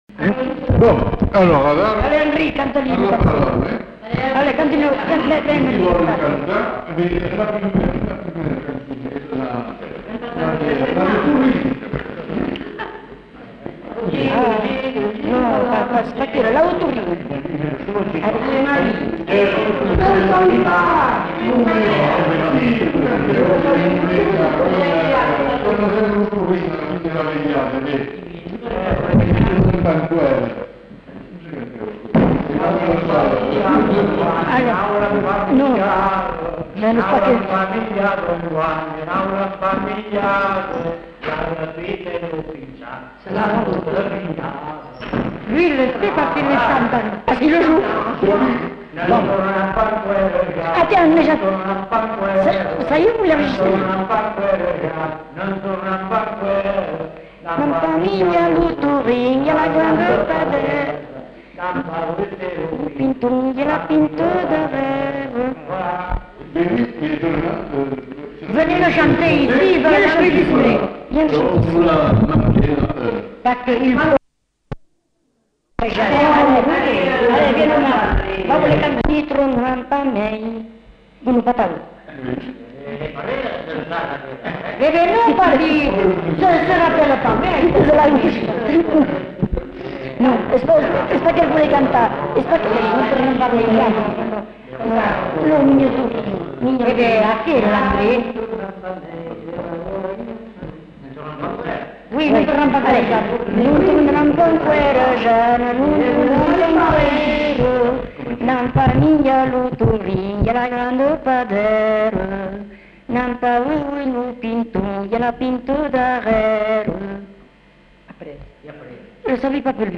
Lieu : Cazalis
Genre : chant
Effectif : 2
Production du son : chanté
Danse : rondeau